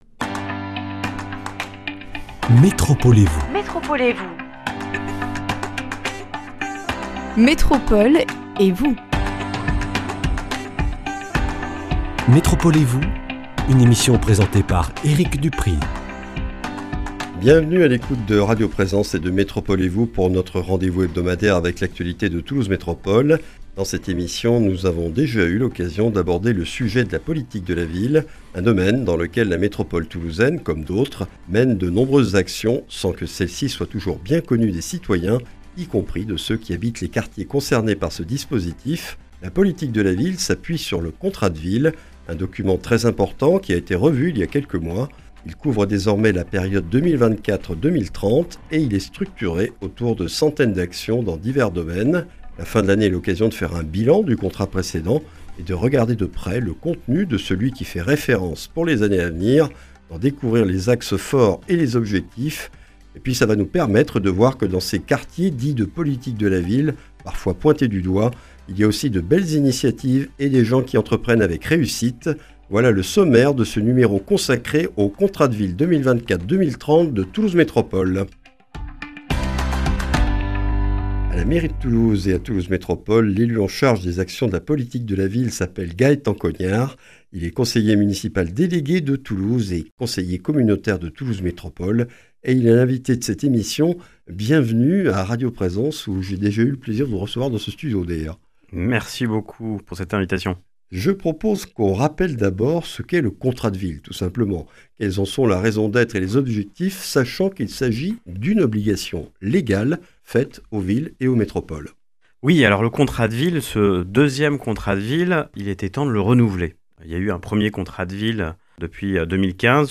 L’invité de ce numéro est Gaëtan Cognard, conseiller municipal délégué de Toulouse et conseiller métropolitain. Il nous présente la politique de la ville et le Contrat de ville 2024-2030 de Toulouse Métropole. Un document élaboré avec les citoyens autour d’actions et dispositifs visant à réduire les inégalités sociales dans 16 quartiers métropolitains et à soutenir les initiatives de leurs habitants.